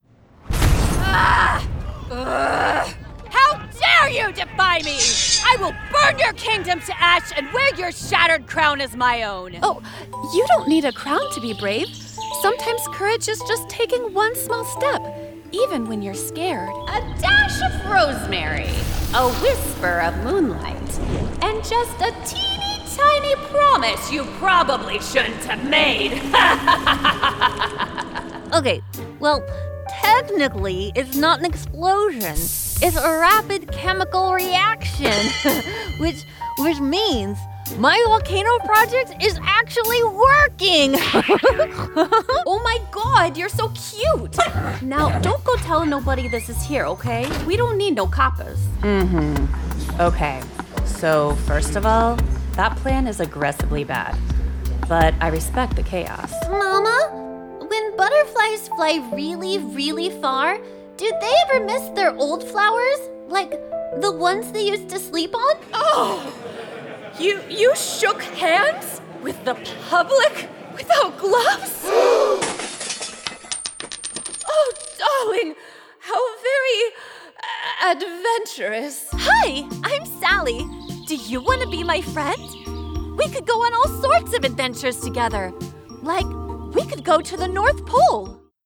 Character, Cartoon and Animation Voice Overs
Adult (30-50) | Yng Adult (18-29)